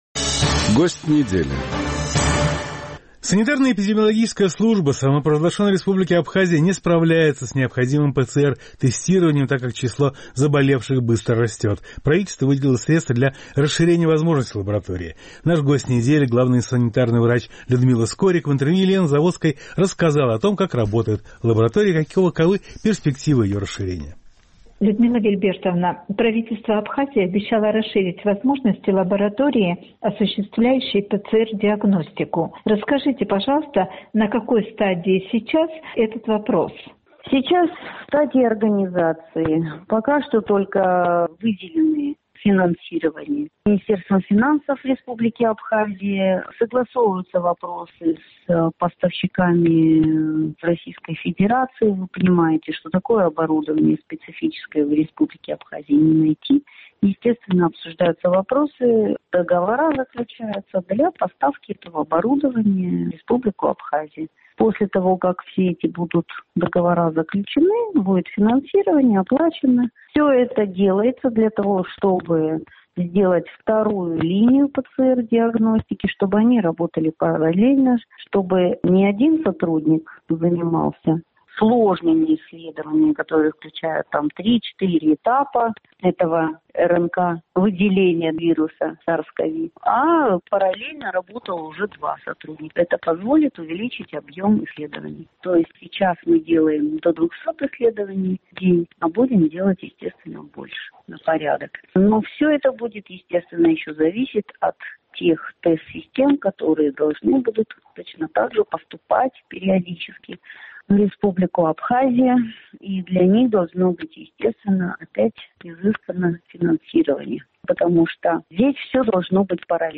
Главный санитарный врач Людмила Скорик в интервью «Эху Кавказа» рассказала о том, как работает лаборатория и каковы перспективы ее расширения.